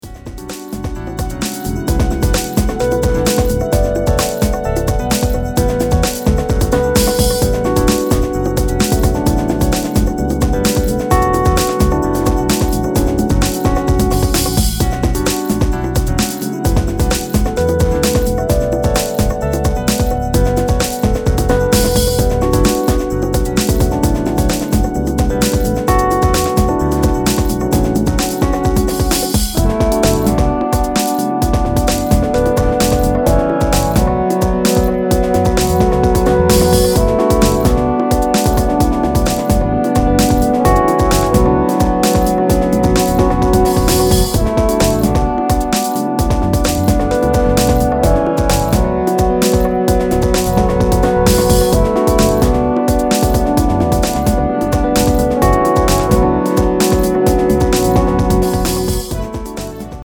Fantastic broken beat from one of Japan’s rising stars.